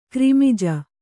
♪ krimija